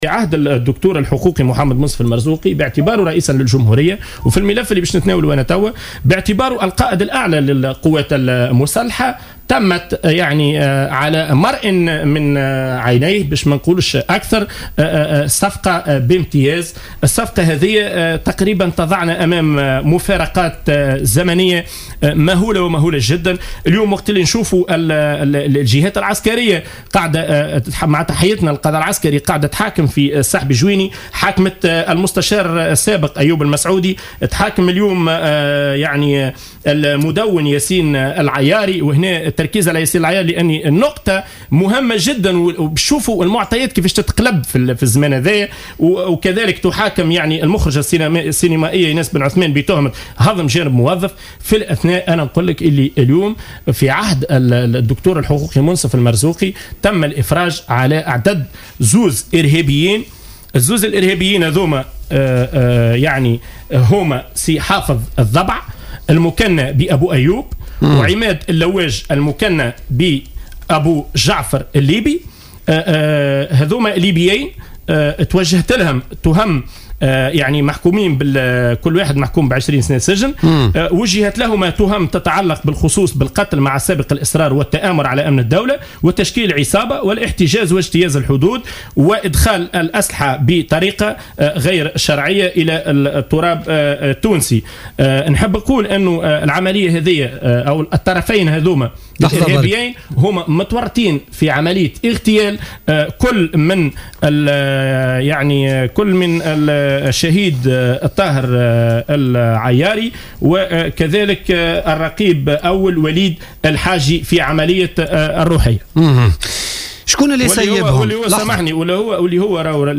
ضيف برنامج بوليتيكا...